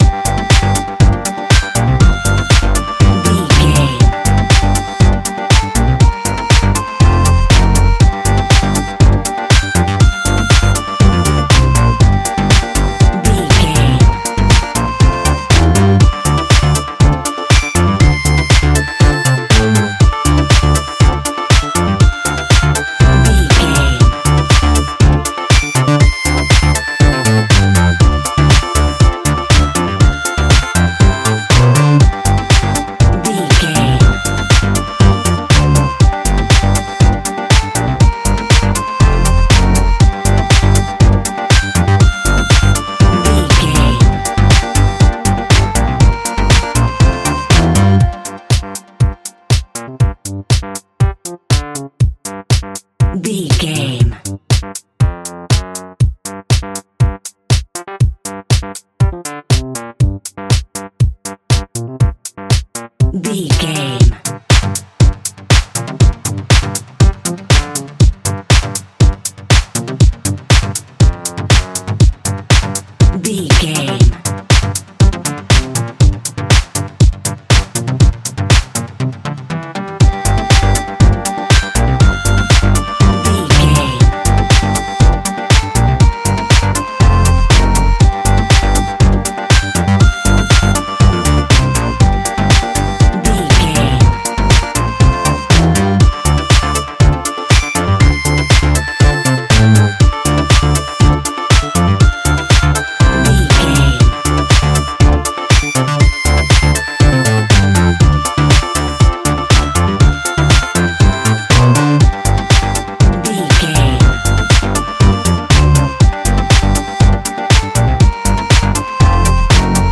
Funky House music.
Aeolian/Minor
D
funky house
electric guitar
bass guitar
drums
hammond organ
fender rhodes
percussion